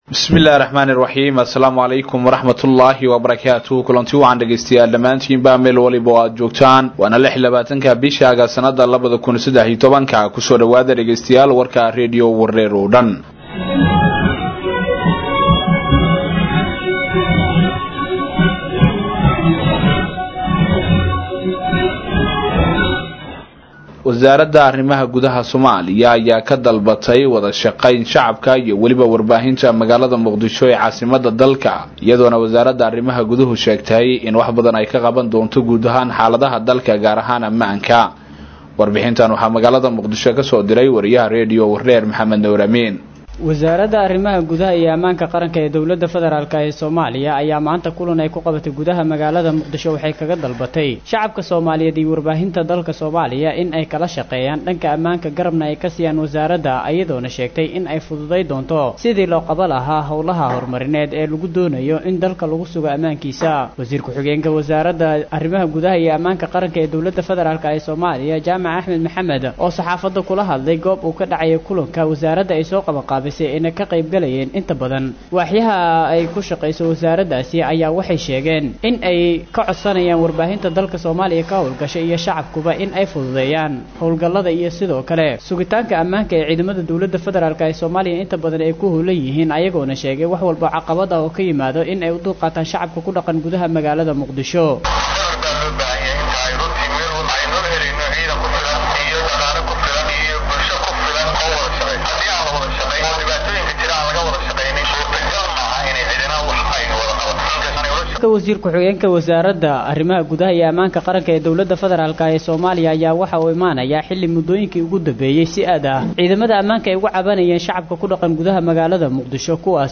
• Audio Daily News